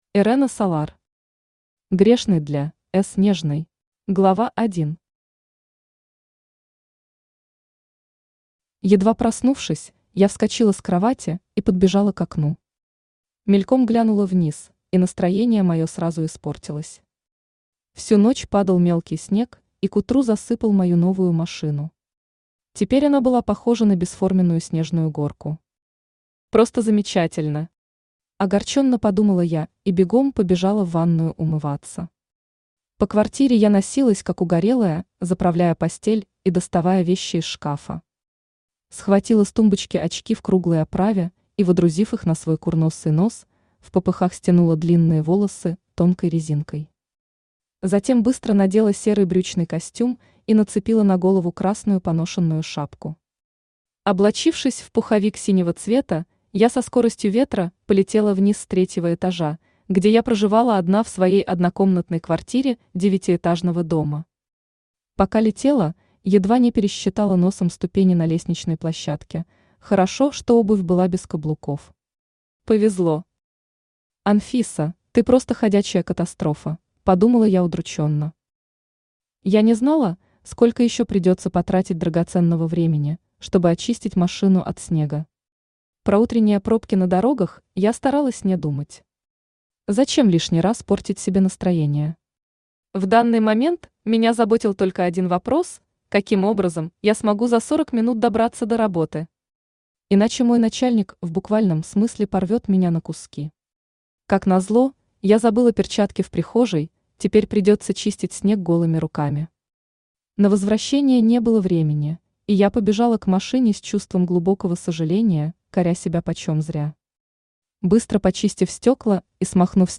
Аудиокнига Грешный для (С)нежной | Библиотека аудиокниг
Aудиокнига Грешный для (С)нежной Автор Ирэна Солар Читает аудиокнигу Авточтец ЛитРес.